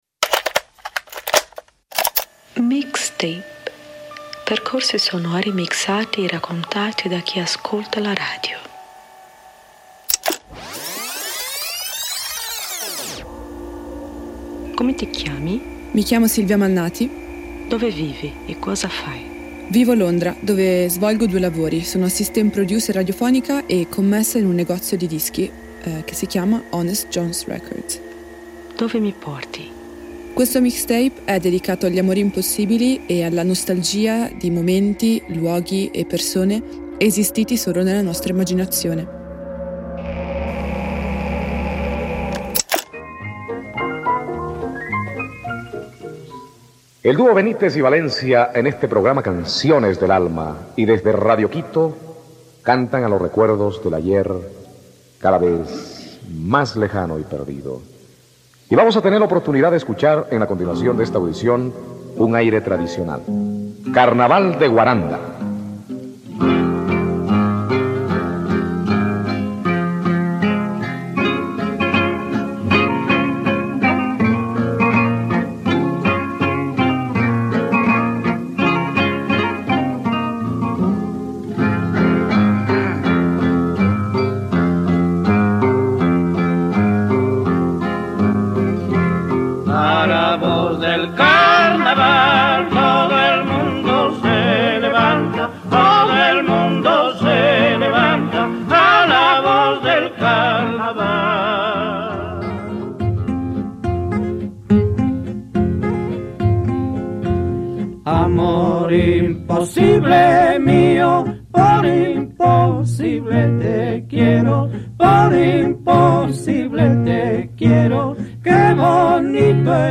Percorsi sonori mixati e raccontati da chi ascolta la radio